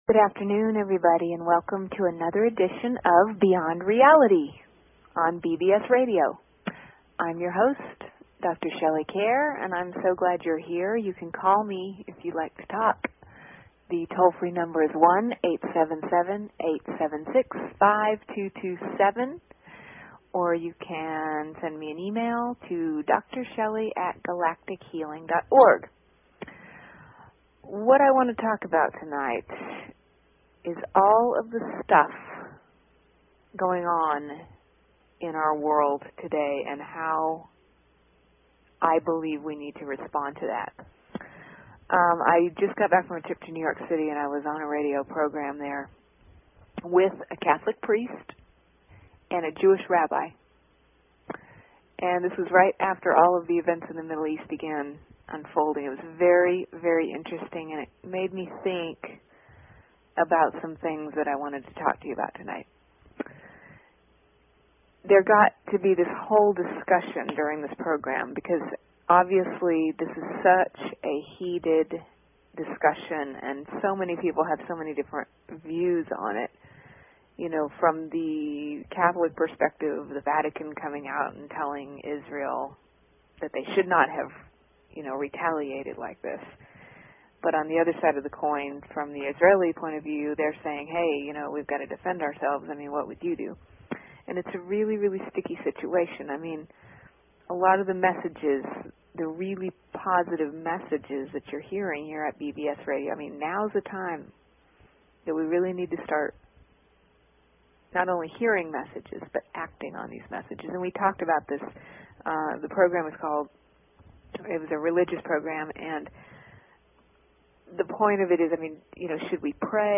Talk Show Episode, Audio Podcast, Beyond_Reality and Courtesy of BBS Radio on , show guests , about , categorized as